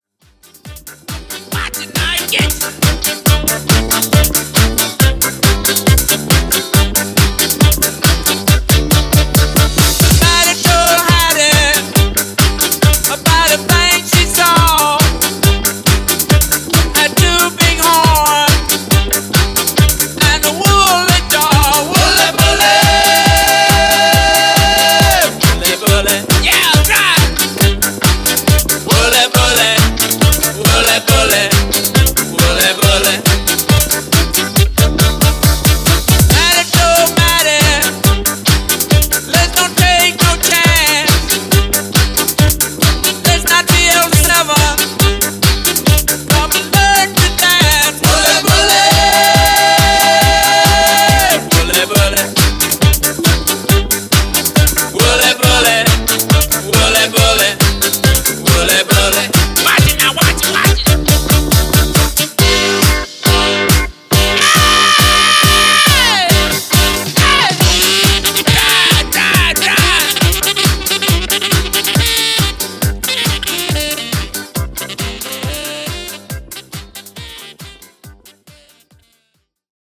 60s Re-Drum)Date Added